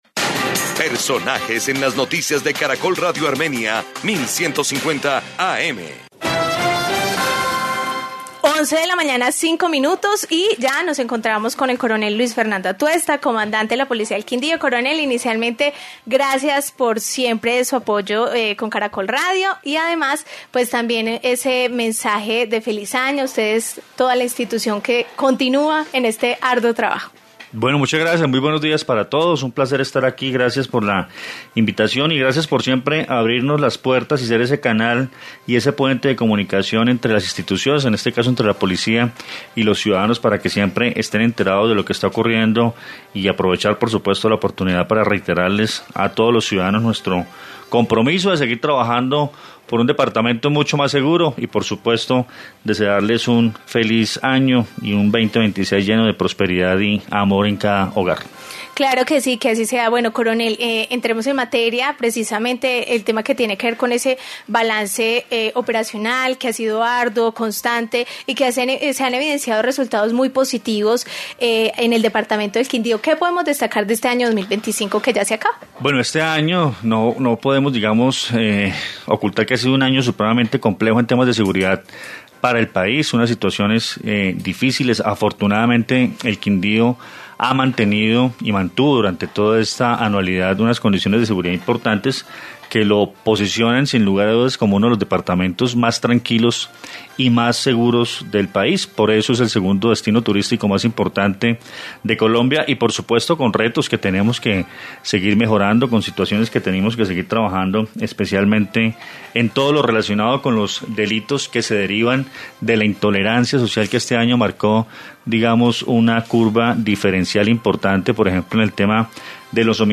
Coronel Luis Fernando Atuesta, comandante de la Policía del Quindío